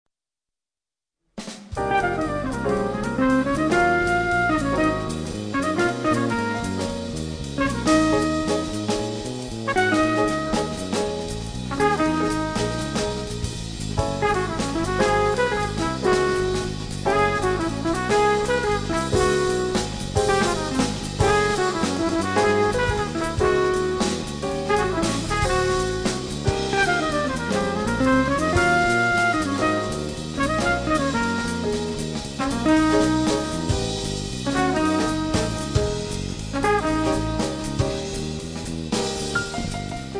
TROMBA E FLICORNO
CHITARRA
PIANO
BASSO